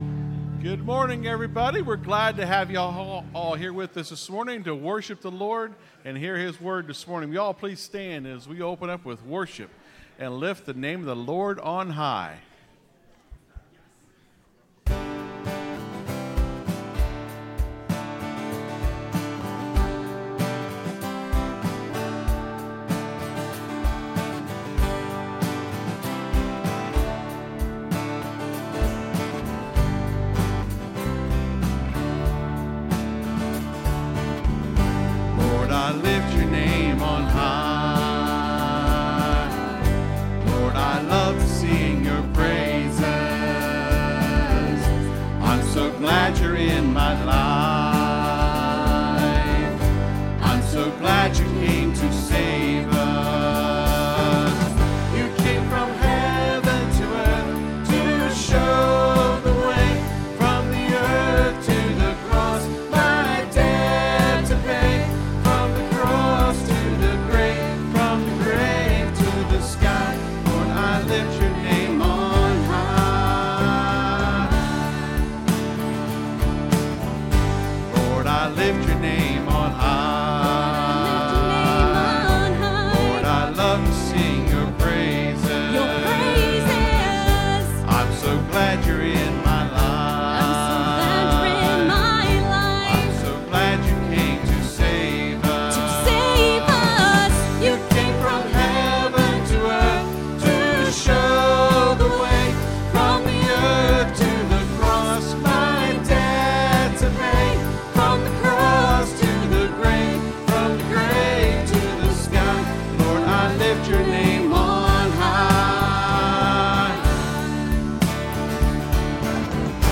(Sermon starts at 20:40 in the recording).